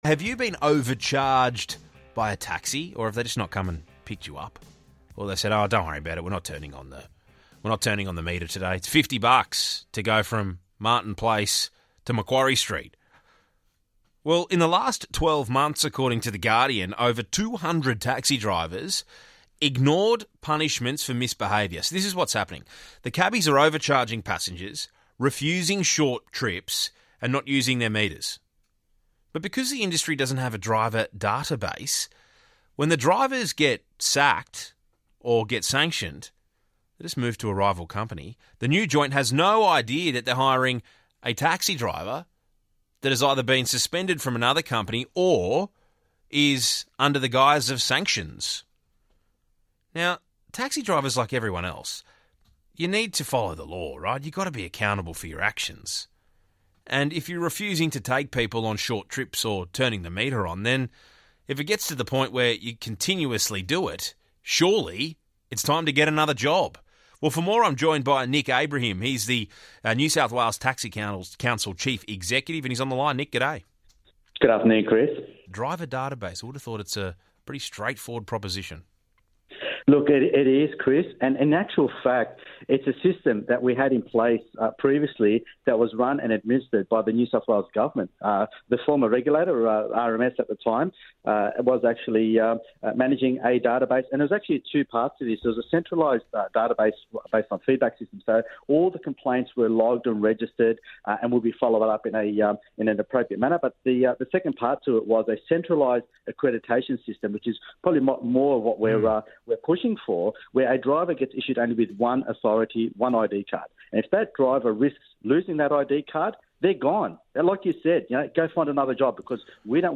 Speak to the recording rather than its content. Source - 2GB Radio